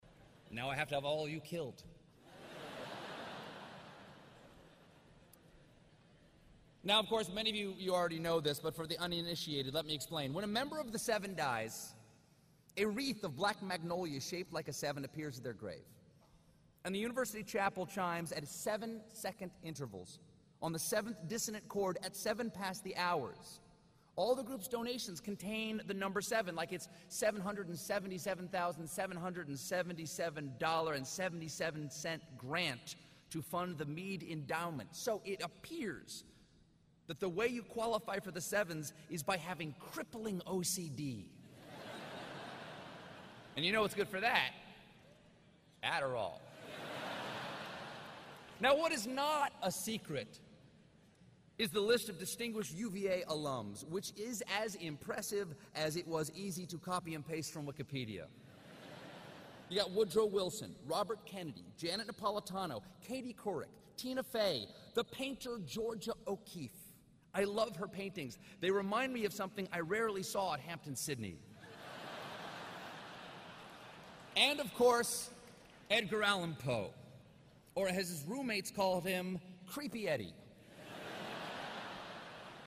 公众人物毕业演讲 第253期:斯蒂芬科尔伯特2013弗吉尼亚大学(6) 听力文件下载—在线英语听力室